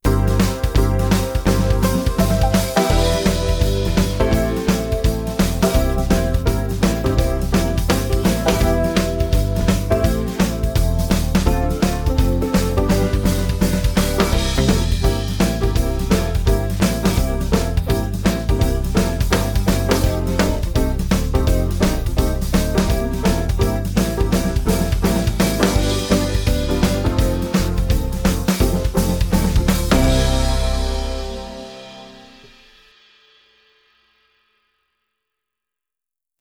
Übungsaufnahmen - Can't Buy Me Love
Runterladen (Mit rechter Maustaste anklicken, Menübefehl auswählen)   Can't Buy Me Love (Playback)